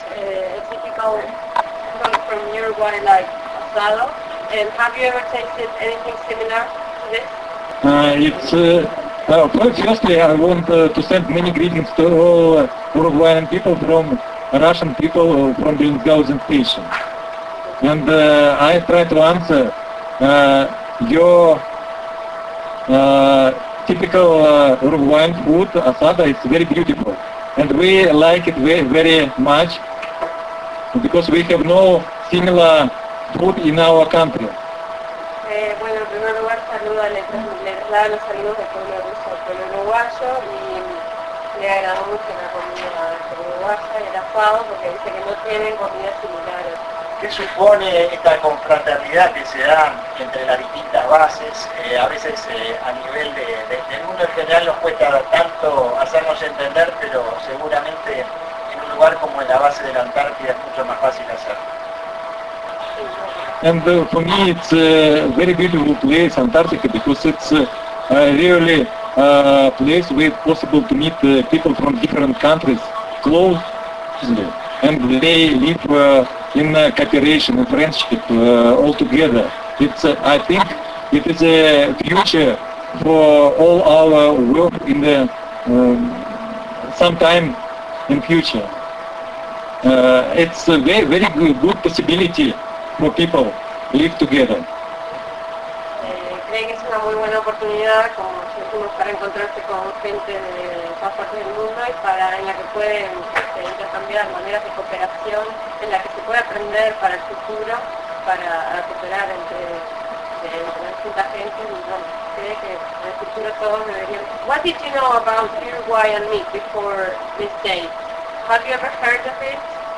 Representante de estación de